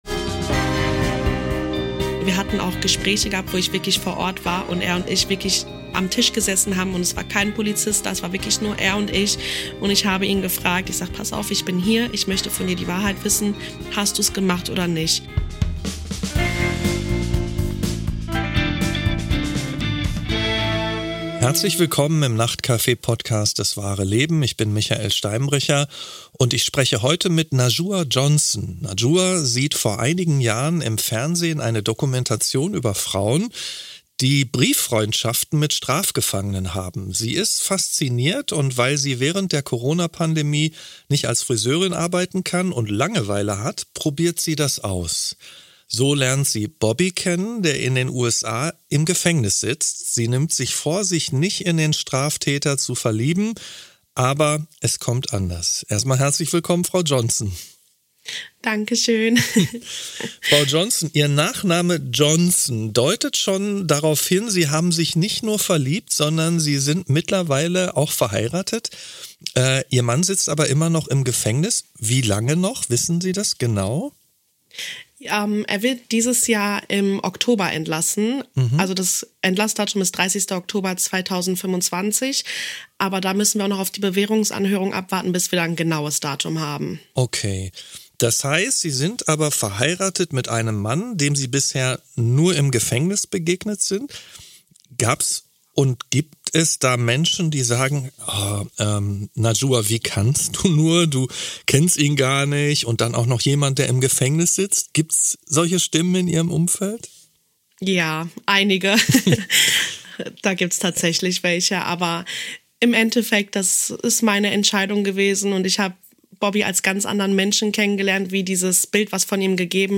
Michael Steinbrecher spricht mit seinen Gästen über das, was wirklich zählt im Leben: Familie, Liebe und Beruf ebenso wie Gesellschaft, Politik und Soziales – von der Geburt bis zum Tod, alles was das Menschsein ausmacht.